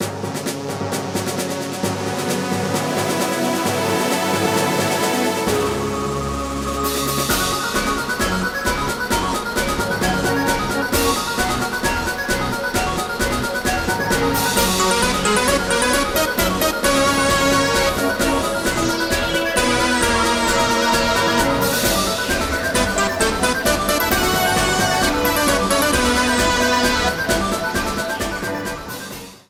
trimmed to 29.5 seconds and faded out the last two seconds